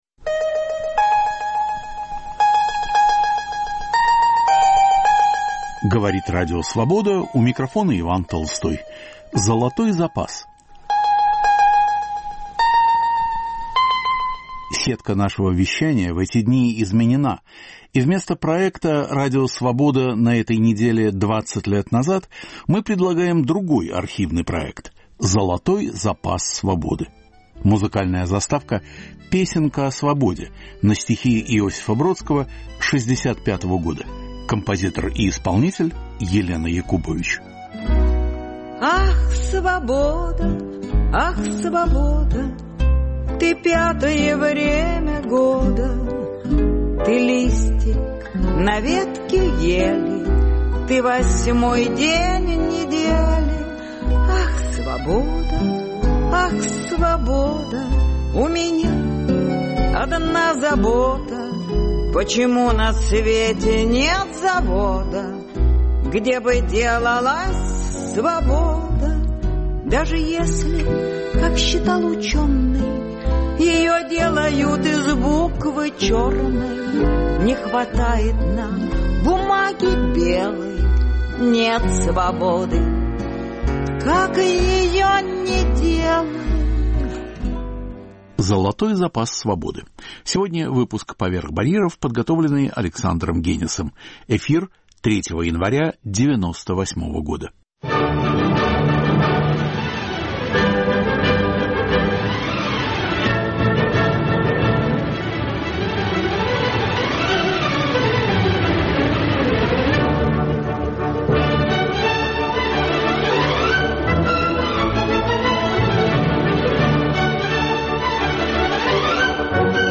В студии музыковед Соломон Волков.